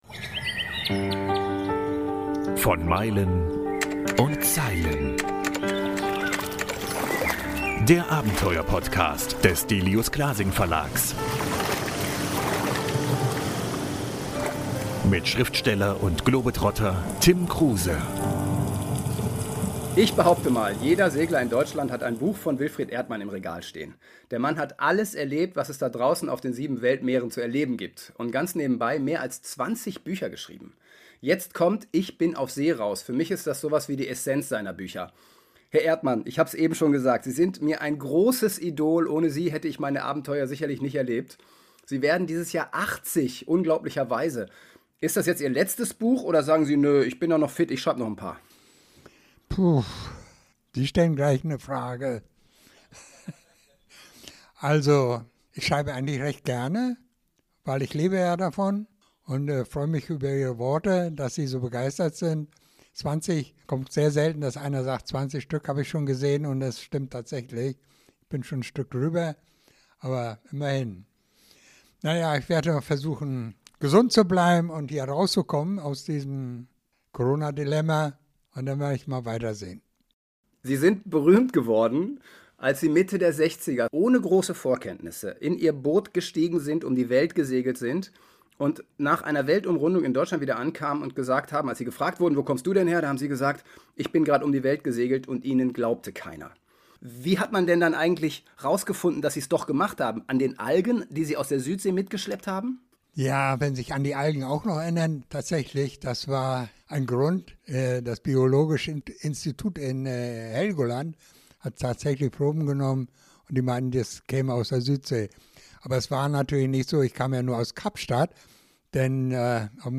Beschreibung vor 5 Jahren Im heutigen Gespräch berichtet Wilfried Erdmann über seine Liebe zum Segeln, seine Begegnung mit Bernard Moitessier, der ihm als jungen Mann den Umgang mit dem Sextanten beibrachte und warum er seine Weltumseglung beweisen musste sowie über sein schriftstellerisches Werk.